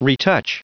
Prononciation du mot retouch en anglais (fichier audio)
Prononciation du mot : retouch